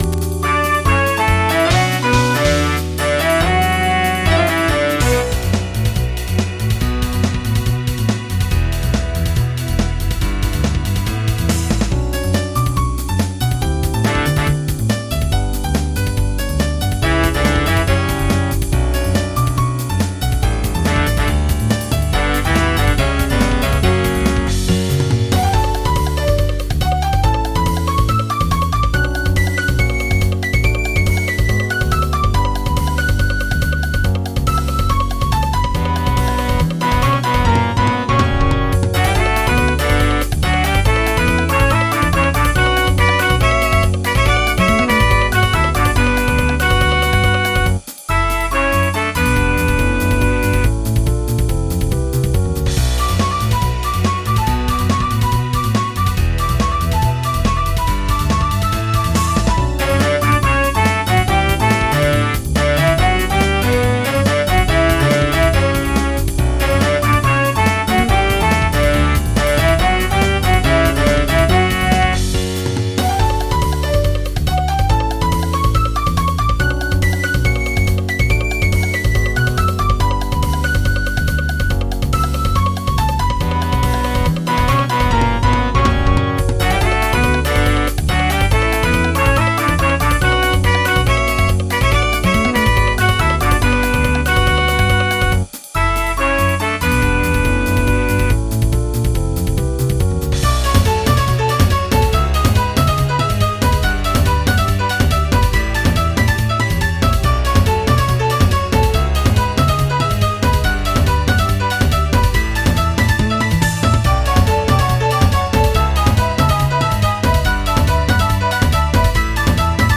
MIDI 43.69 KB MP3 (Converted) 2.08 MB MIDI-XML Sheet Music
Normal Battle Theme